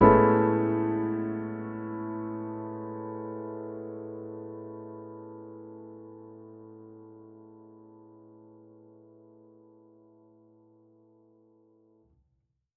Index of /musicradar/jazz-keys-samples/Chord Hits/Acoustic Piano 1
JK_AcPiano1_Chord-Amaj9.wav